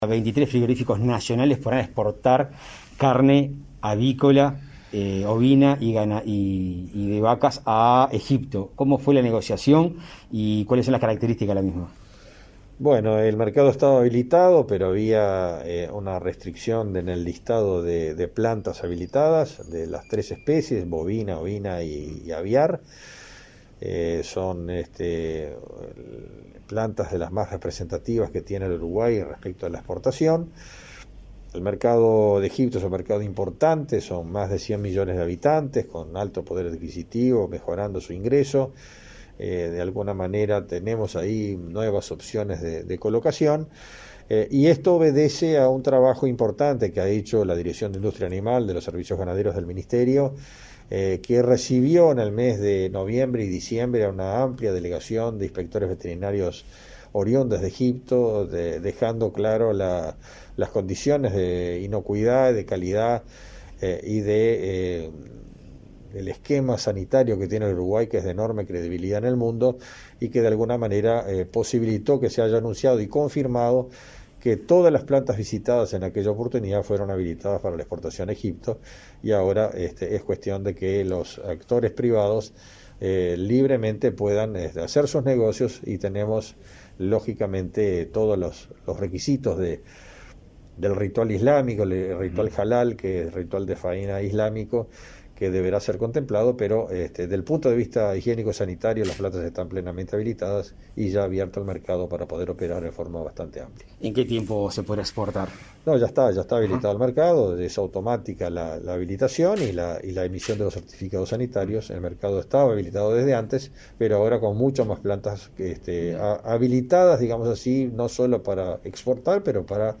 Entrevista al ministro de Ganadería, Fernando Mattos